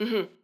VO_ALL_Interjection_13.ogg